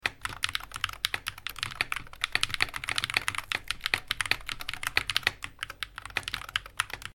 Akko TAC75 He w/ AstroLink sound effects free download
Akko TAC75 He w/ AstroLink Switches